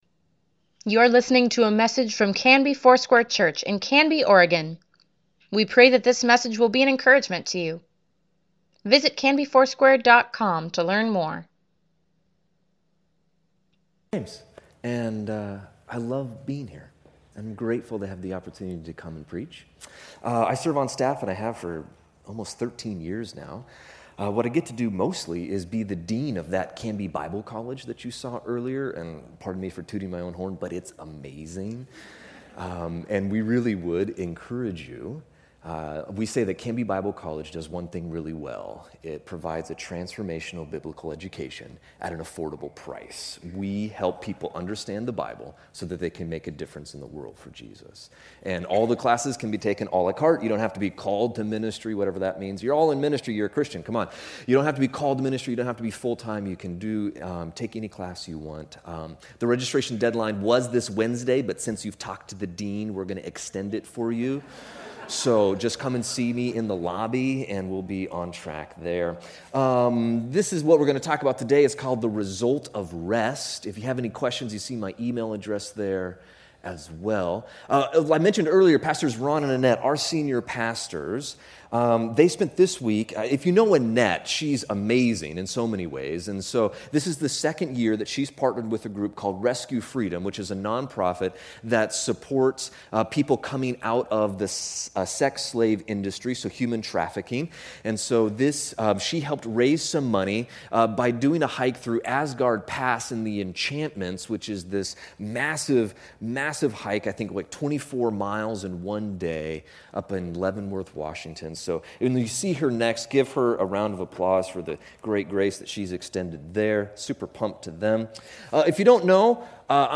Weekly Email Water Baptism Prayer Events Sermons Give Care for Carus The Result of Rest August 19, 2018